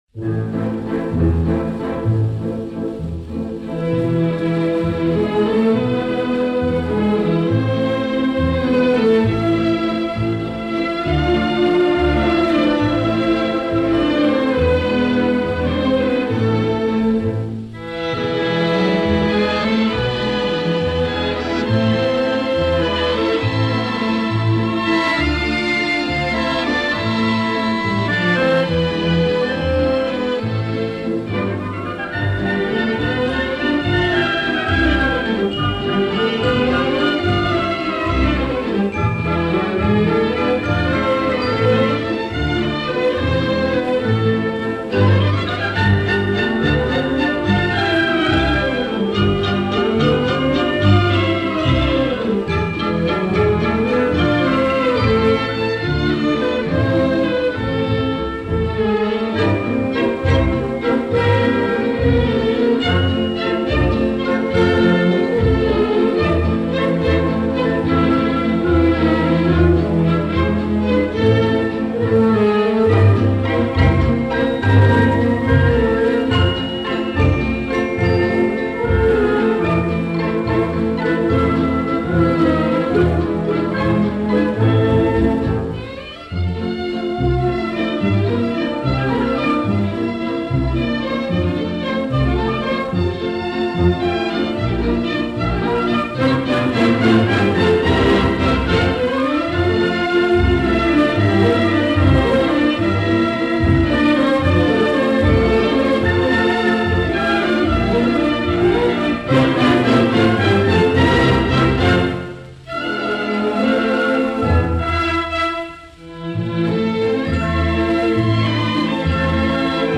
мелодия без слов.